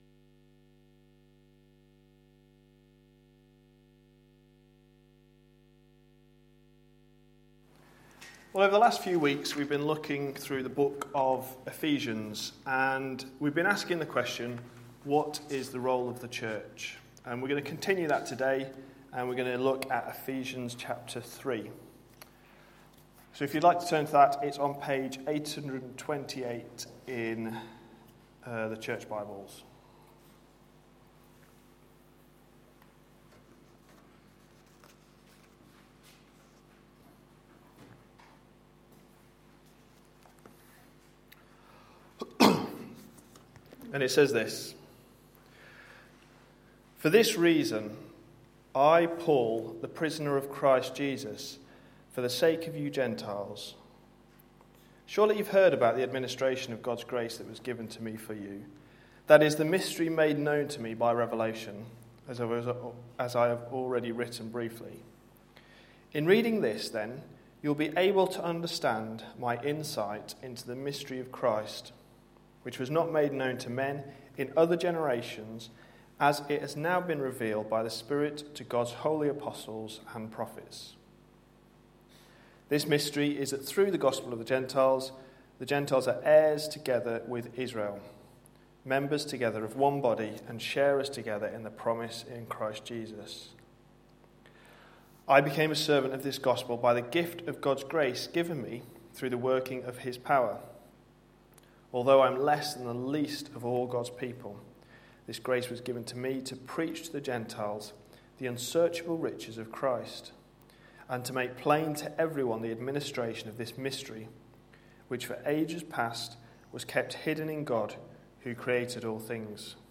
A sermon preached on 9th June, 2013, as part of our Ephesians series.